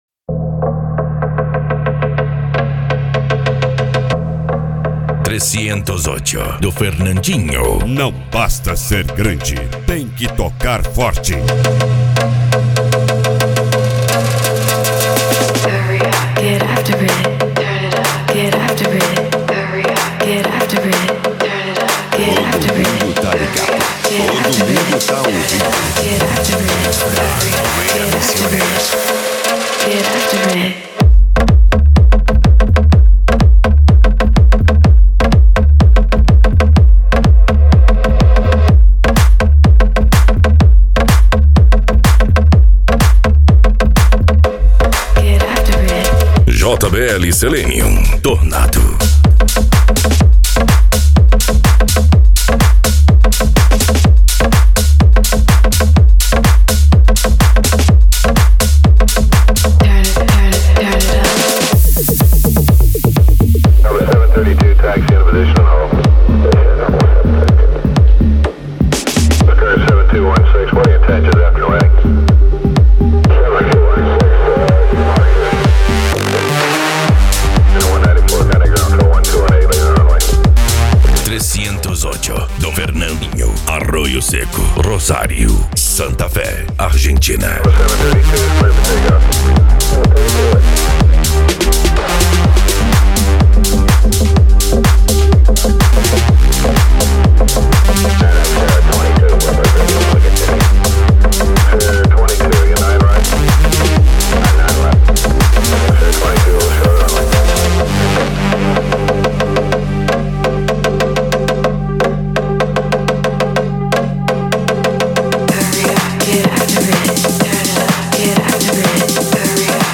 Bass
Cumbia
Funk
PANCADÃO
Psy Trance
Remix